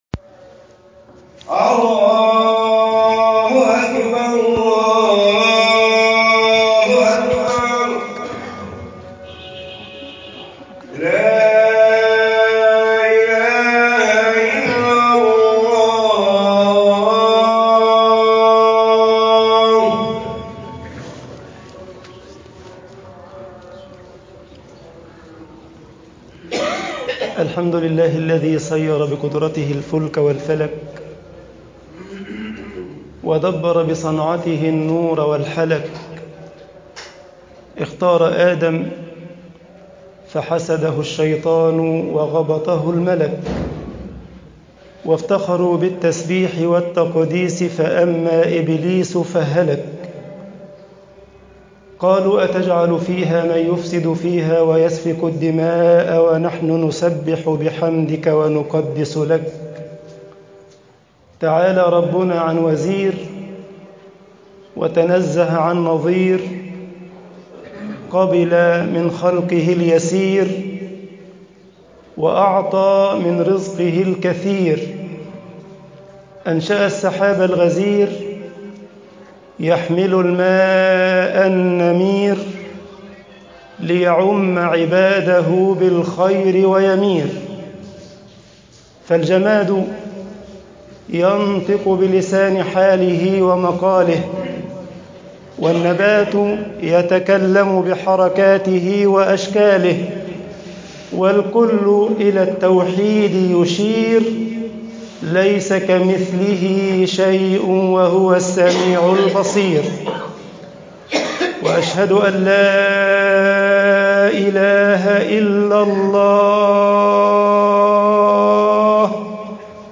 خطب الجمعة - مصر طبقات المستجيبين